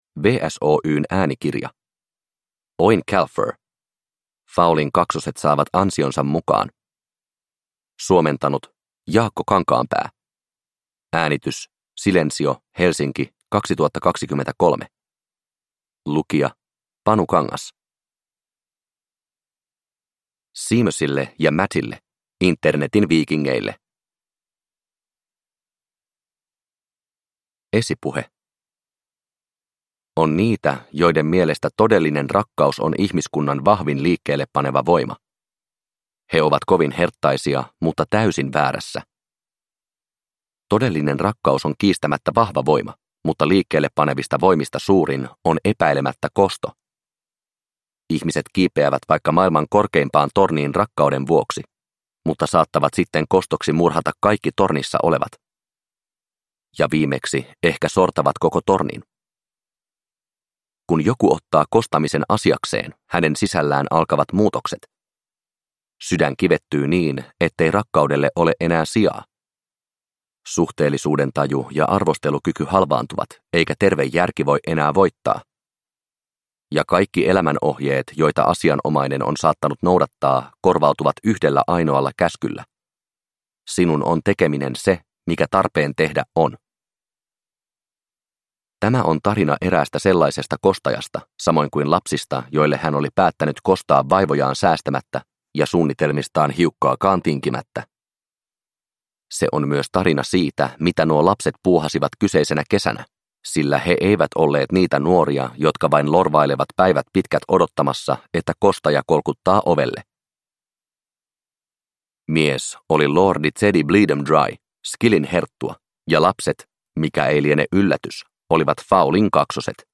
Fowlin kaksoset saavat ansionsa mukaan – Ljudbok